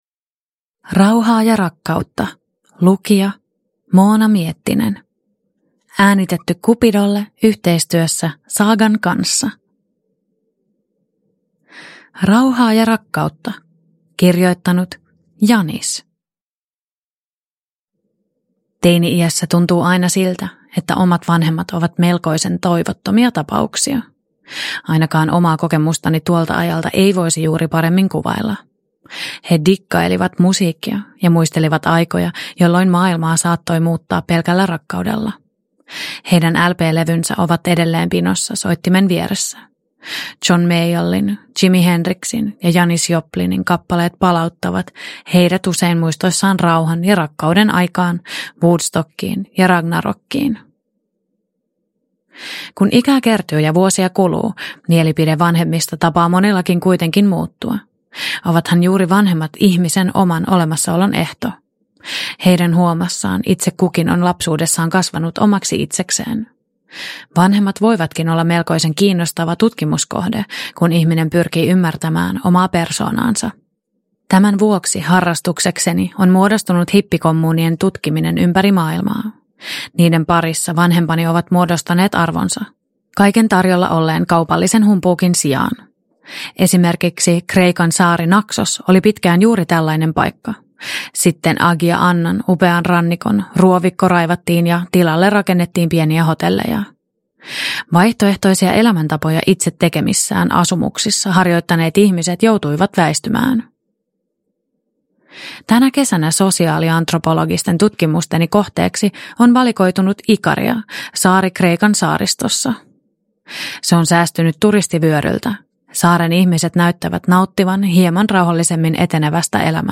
Rauhaa ja rakkautta (ljudbok) av Cupido